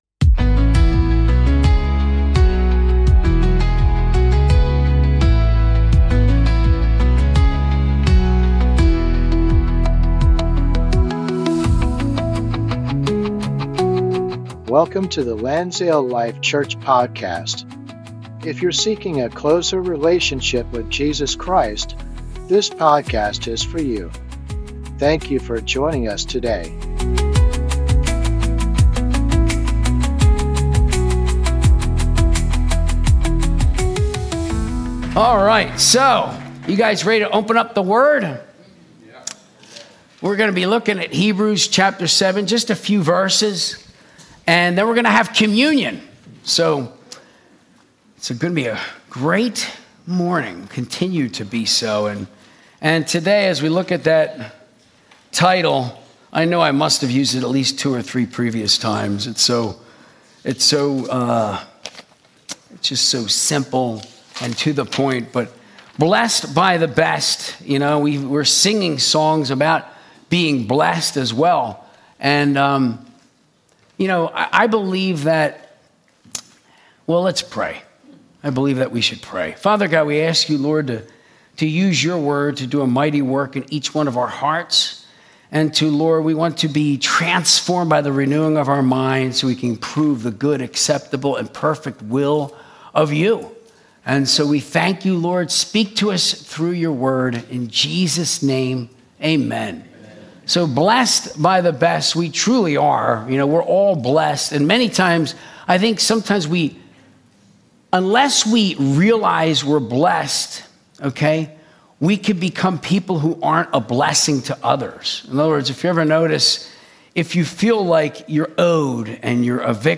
Sunday Service - 2025-09-07